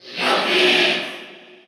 Ludwig_Cheer_Russian_SSBU.ogg.mp3